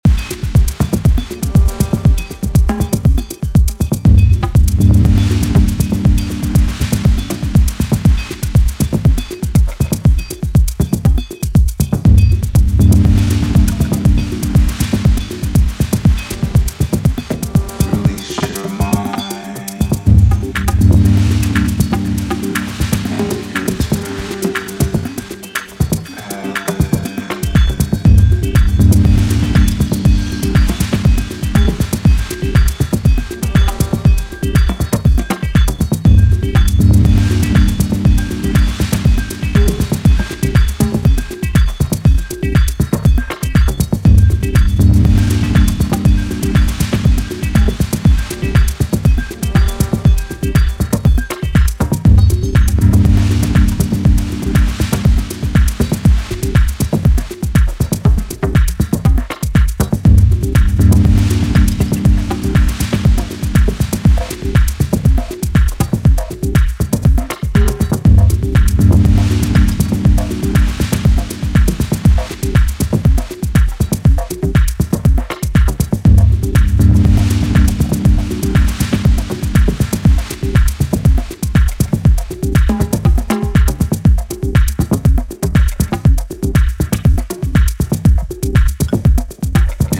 アトモスフェリックに深いところまで先導するミニマル・ダブハウス